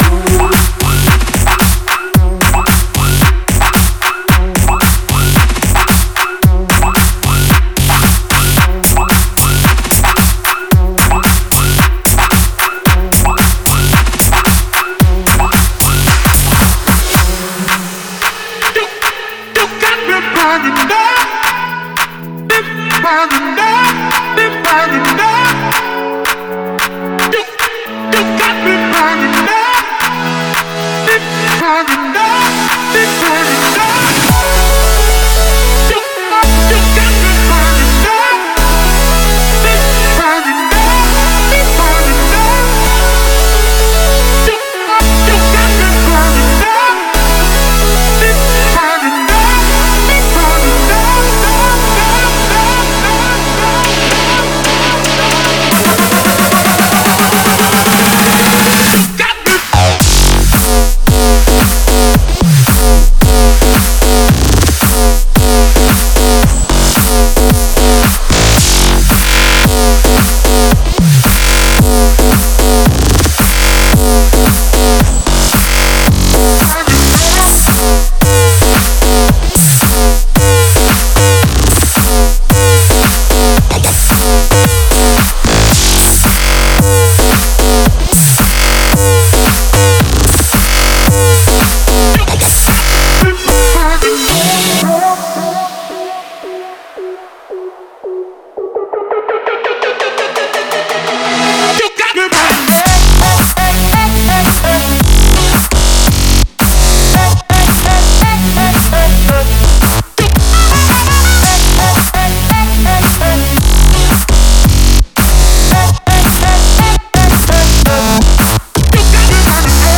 BPM112
MP3 QualityMusic Cut